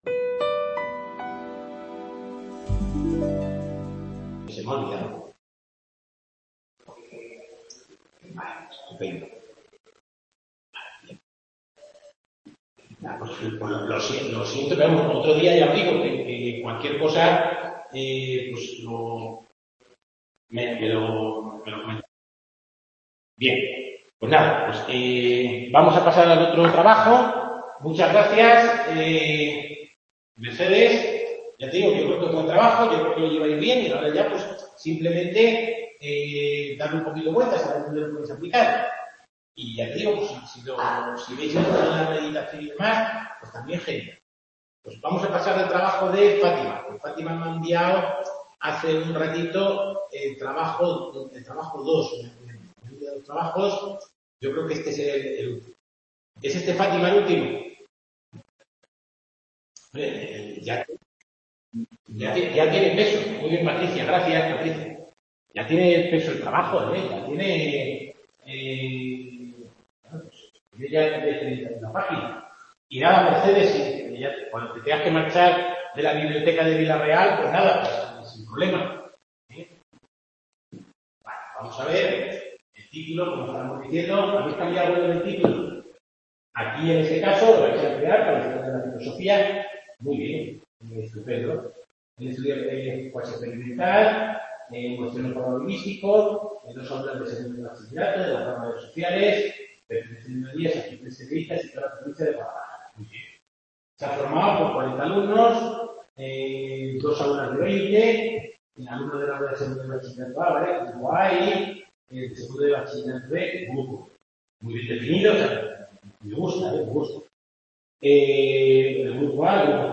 Tutoria